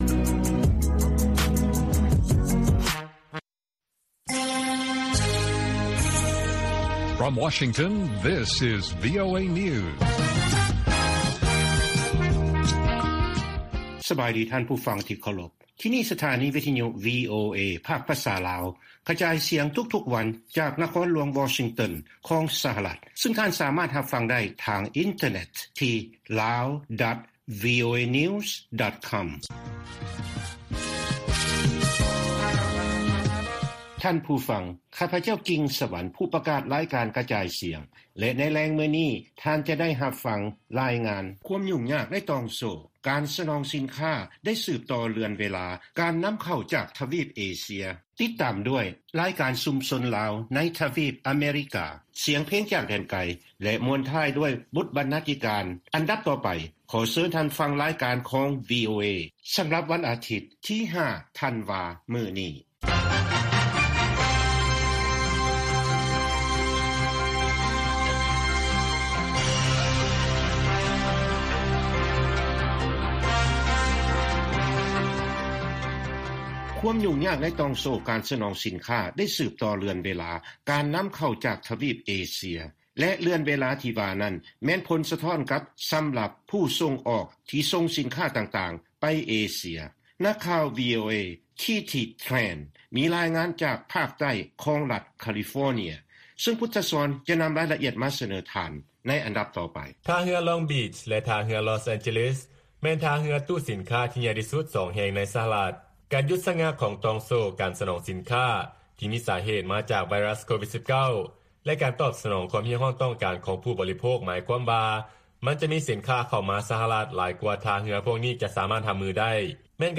2 ລາຍງານເລື້ອງ ຕ່ອງໂສ້ການສະໜອງສິນຄ້າຢຸດຊະງັກ ເຮັດໃຫ້ການສົ່ງອອກຂອງສະຫະລັດ ໄປໃຫ້ຜູ້ບໍລິໂພກ ຢູ່ເອເຊຍ ມີການຊັກຊ້າ. 3. ລາຍການສຽງເພງຈາກແດນໄກ ປະຈຳສຳປະກາຂອງວີໂອເອ.